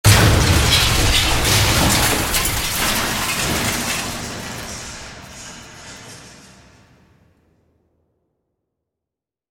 Звуки взрыва танка
Звук уничтожения боевой машины